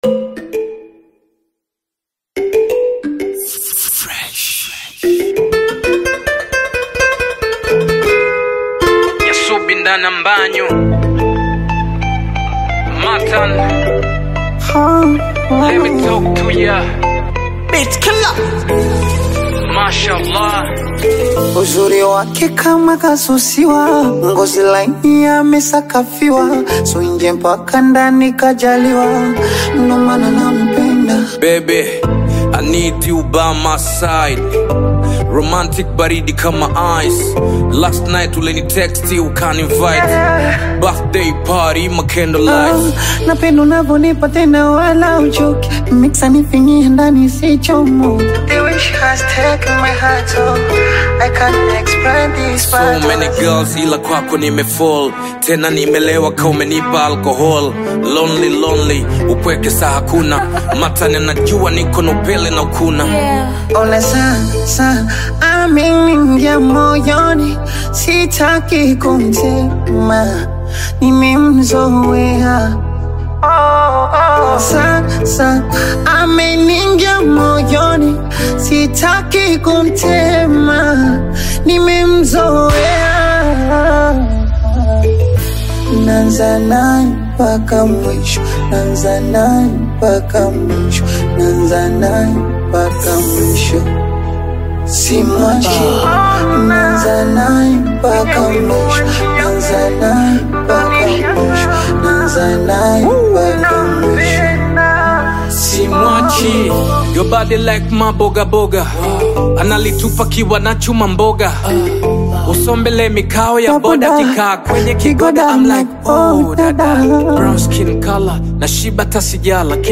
Tanzanian bongo flava artist
African Music You may also like